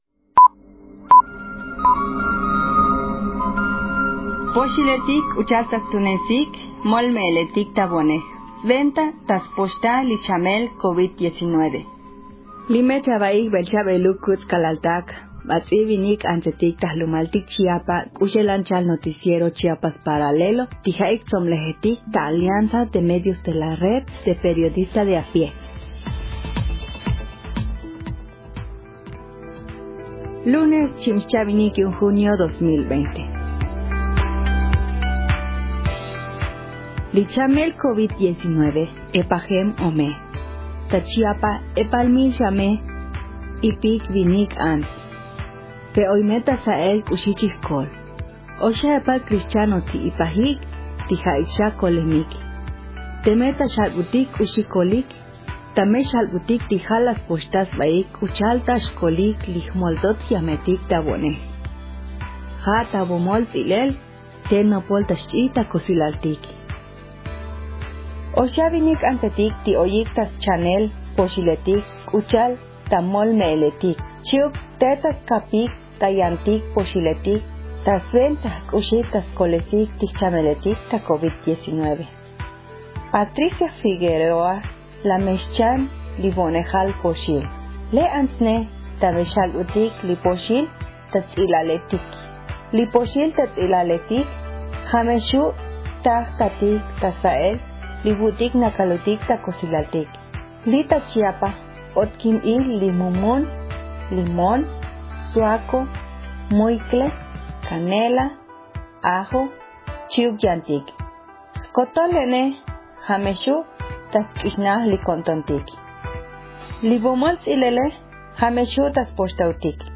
Resumen informativo COVID-19